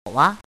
“哇”读音
wa
哇字注音：ㄨㄚ/˙ㄨㄚ
国际音标：wɑ˥;/wɑ˥˧